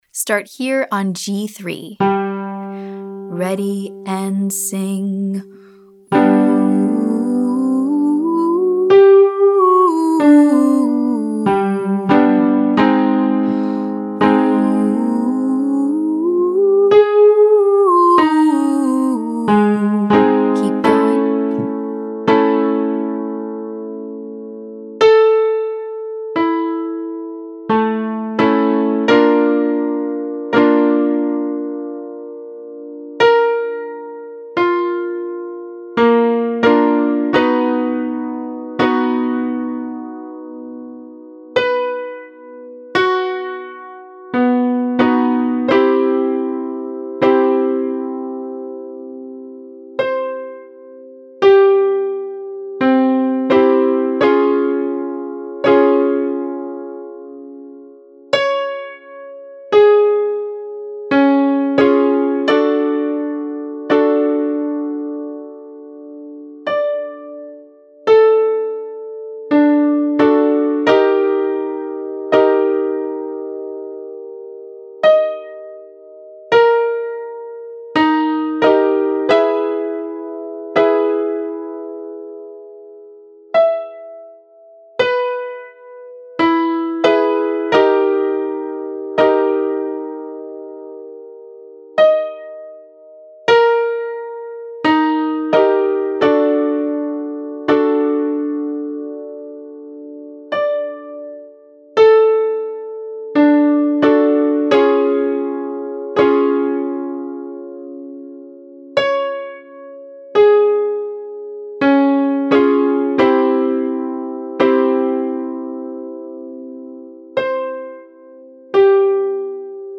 1. Exercise 1: Full major scale, ascending & descending. (Syllables: DOO, HOO, OO)
2. Exercise 2: 1.5 octave scale, ascending & descending. (Syllables: DOO, HOO, OO)